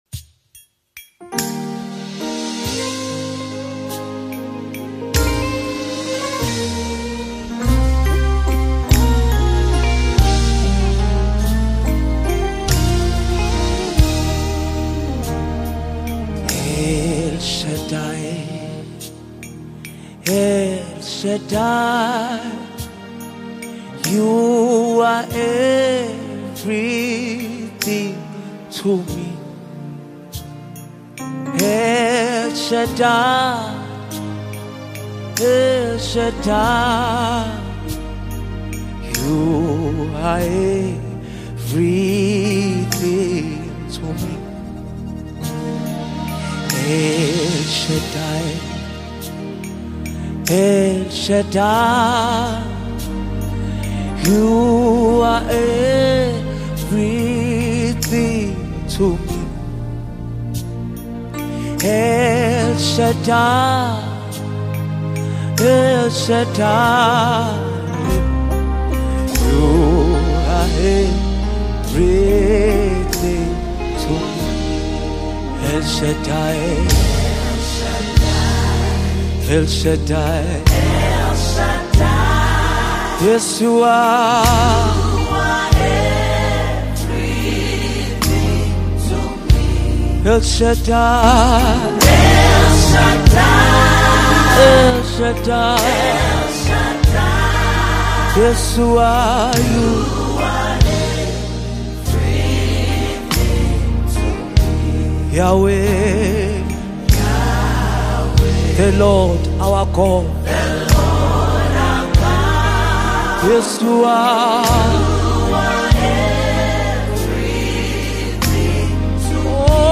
A powerful worship song that glorifies God’s name
Soul-stirring vocals
📅 Category: South African Most Loved Worship Song
Download, listen, and share this powerful gospel song today!